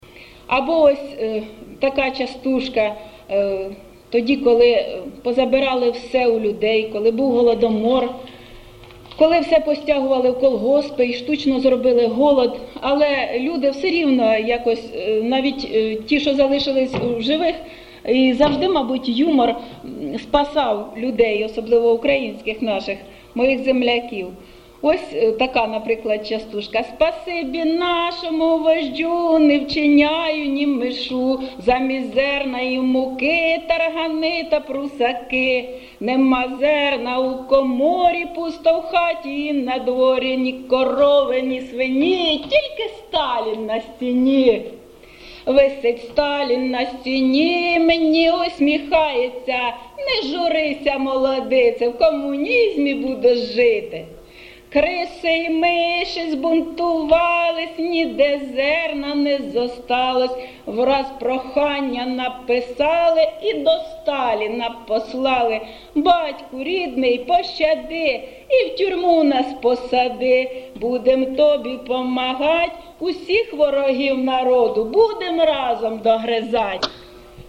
ЖанрТриндички, Частівки
Місце записум. Костянтинівка, Краматорський район, Донецька обл., Україна, Слобожанщина